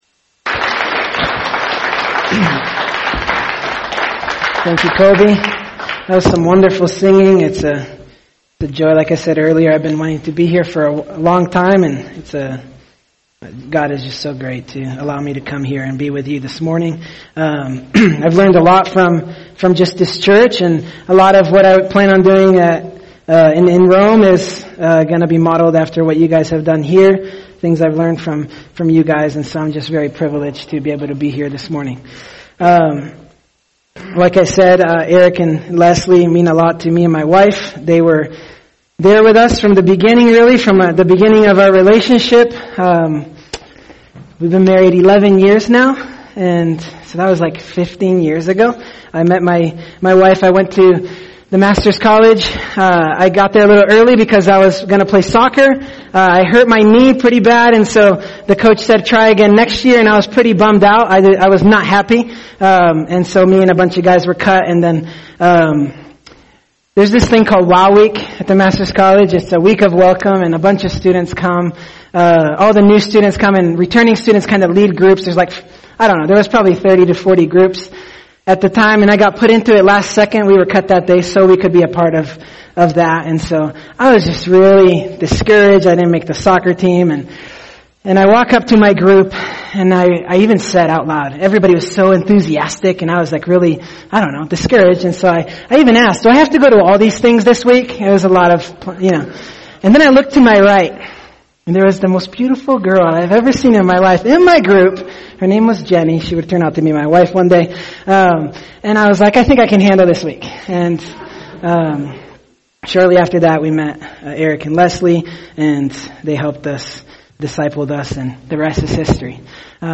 [sermon] Psalm 145 – A God Worth Talking About | Cornerstone Church - Jackson Hole